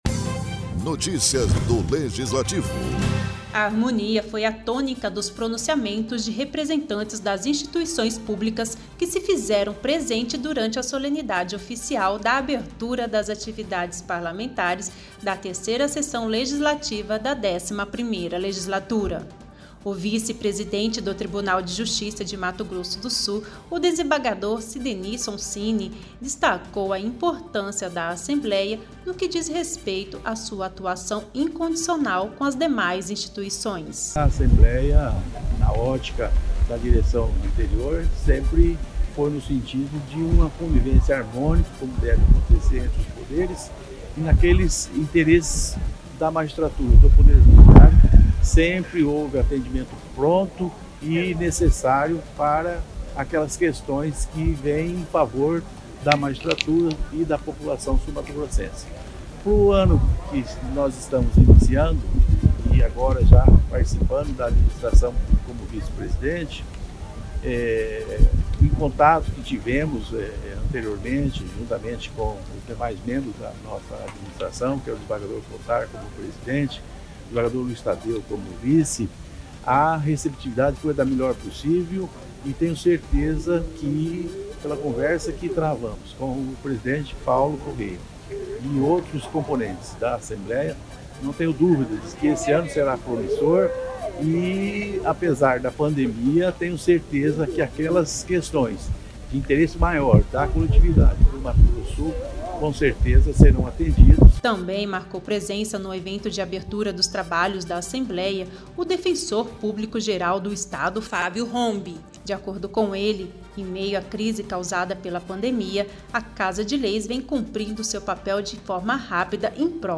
A harmonia foi à tônica dos pronunciamentos de representantes das instituições públicas que se fizeram presente durante a solenidade oficial da abertura das atividades parlamentares da 3ª sessão legislativa da 11ª legislatura realizada nesta terça-feira(2).